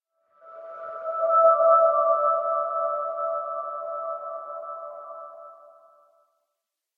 cave2.ogg